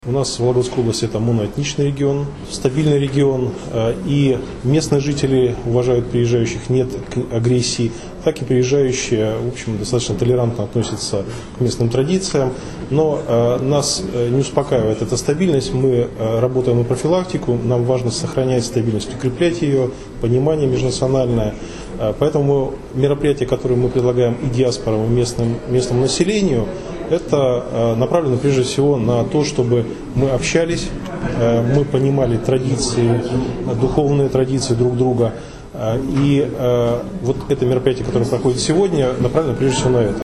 Алексей Макаровский рассказывает об адаптации иностранных граждан
«Нас не успокаивает эта стабильность. Мы работаем на профилактику, чтобы укреплять межнациональное понимание. Мероприятия, которые мы предлагаем диаспорам и местному населению, направлены прежде всего на общение и понимание духовной традиции друг друга», - рассказал ИА «СеверИнформ» заместитель губернатора области Алексей Макаровский.